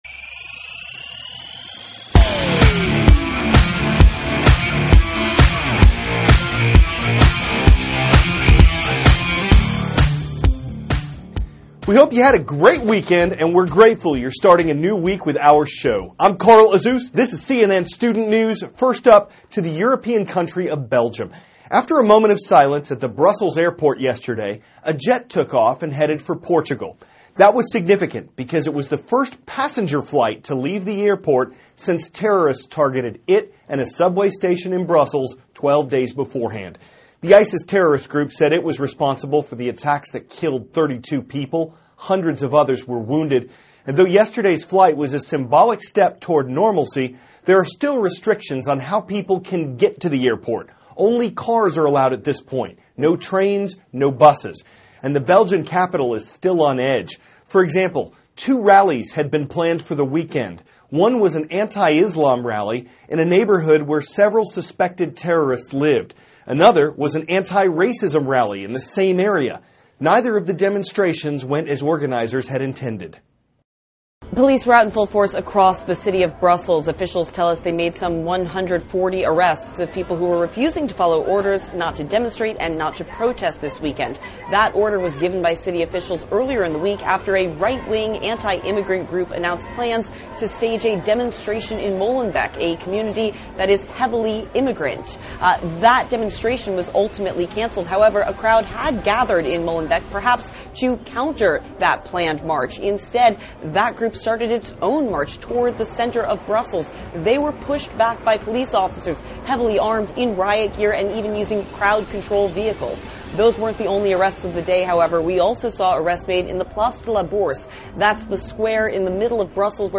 (CNN Student News) -- April 4, 2016 First Passenger Flight Leaves Brussels Since March 22 Terror Attacks; Lost Language Found on Buried Stone Slab; Wisconsin`s Political Significance; New Music Theory THIS IS A RUSH TRANSCRIPT.